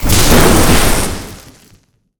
electric_lightning_blast_05.wav